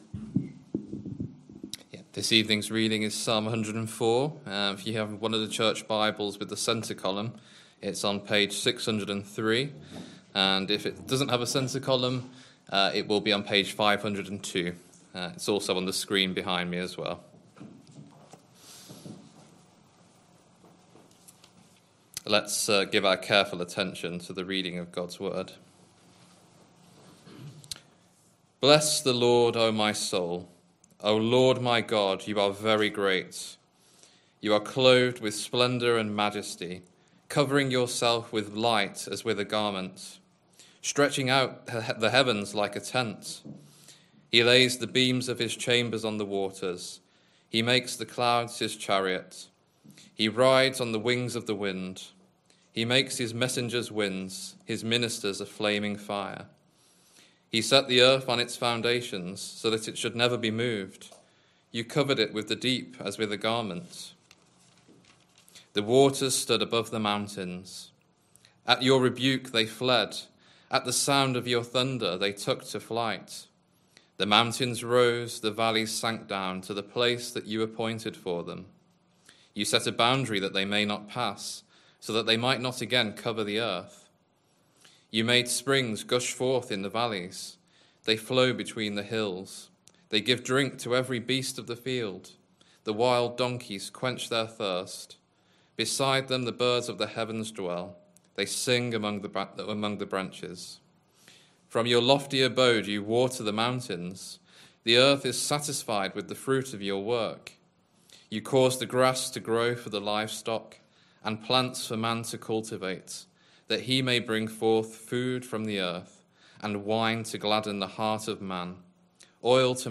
Christ Church Sermon Archive
Sunday PM Service Sunday 18th January 2026 Speaker